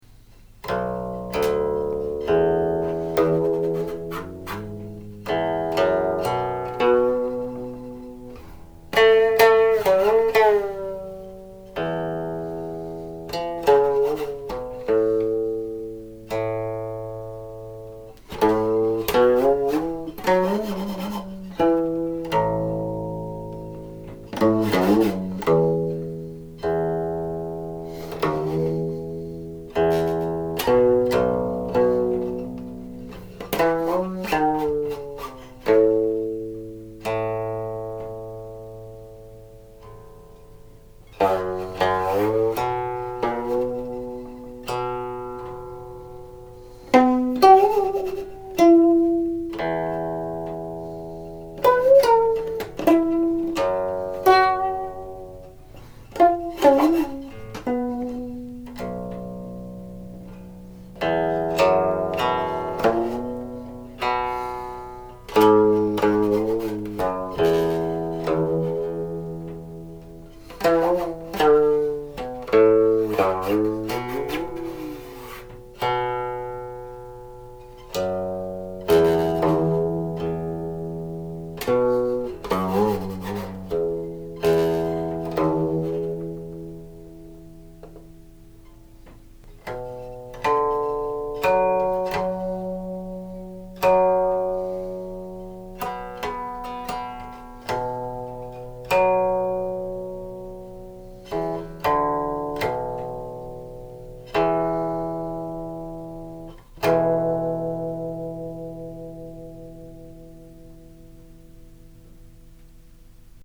playing (but not singing).
Hence, for example, almost all phrases in these interpretations begin on an upbeat.